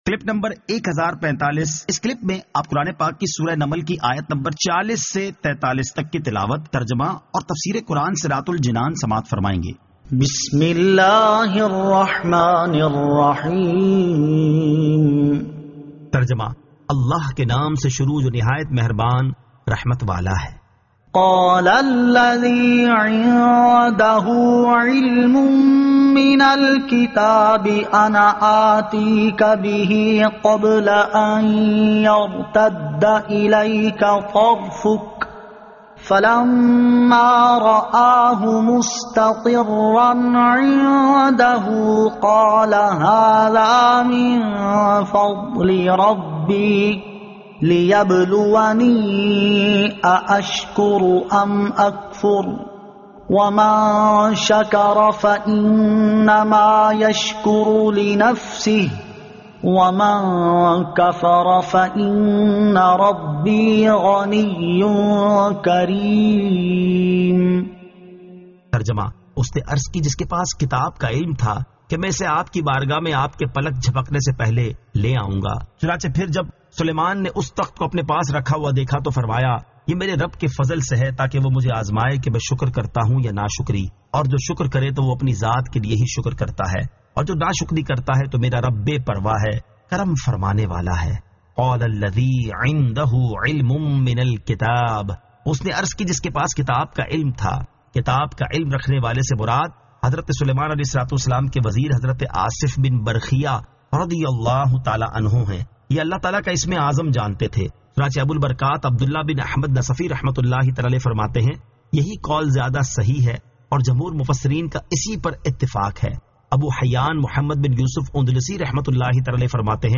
Surah An-Naml 40 To 43 Tilawat , Tarjama , Tafseer